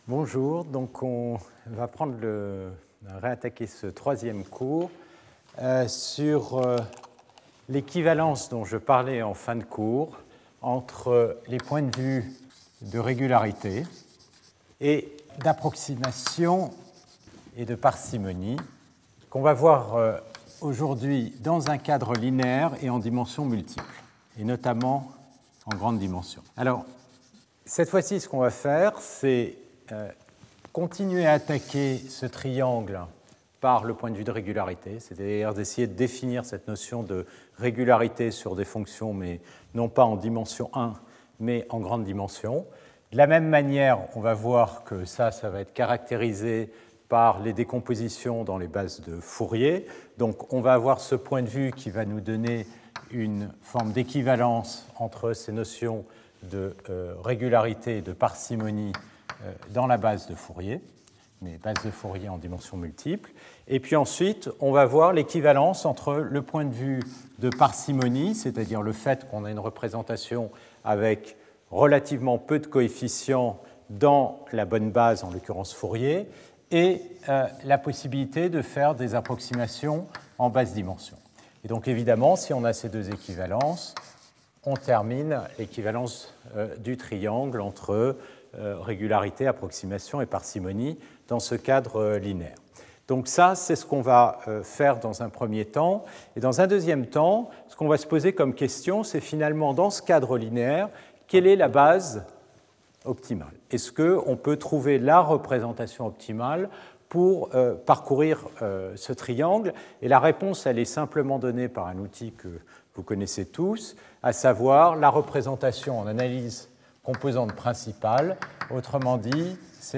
Intervenant(s) Stéphane Mallat Professeur du Collège de France